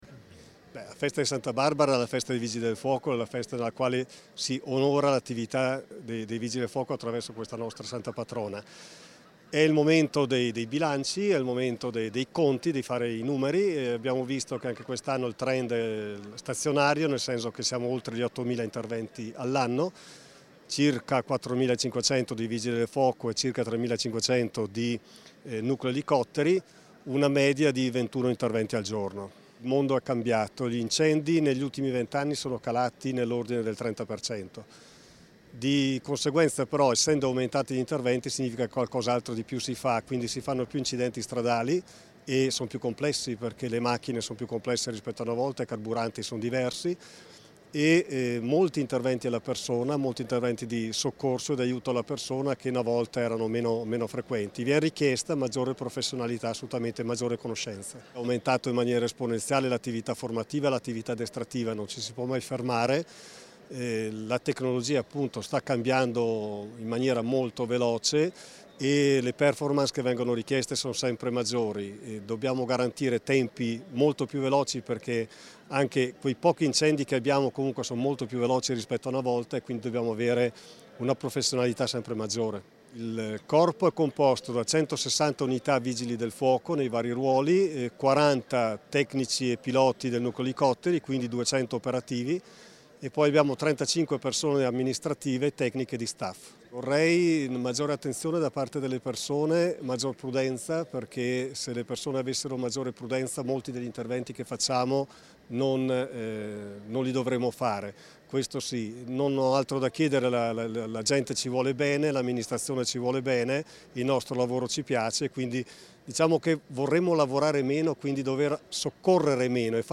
Alla caserma del Corpo permanente dei Vigili del Fuoco di Trento la celebrazione della festa di Santa Barbara